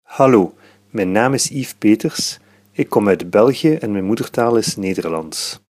For all those who do not read IPA transcriptions fluently, there are some audio recordings: Whenever you see a ? symbol next to a name, you can click on it to listen to the speaker’s own pronunciation of their name. Most speakers say something along the lines of ‘Hi, my name is […], I come from […] and my mother tongue is […]’ – all that in (one of) their native language(s).